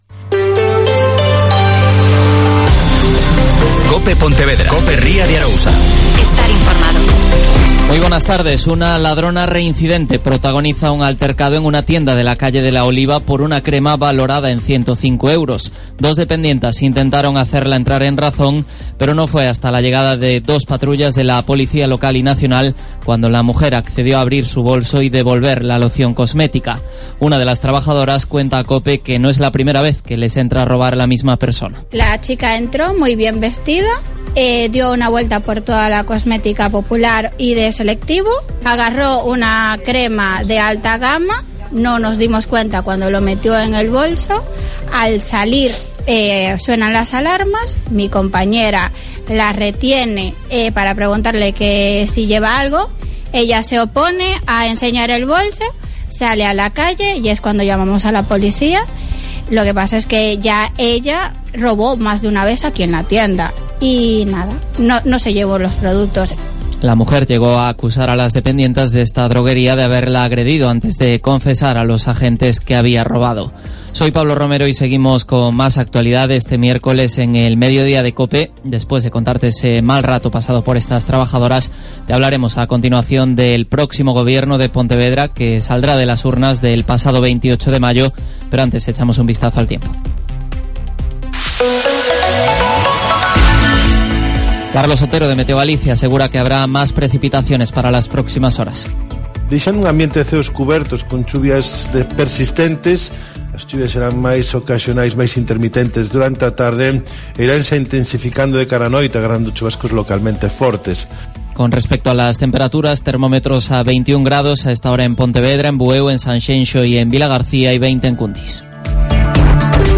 Mediodía COPE Ría de Arosa (Informativo 14:20h)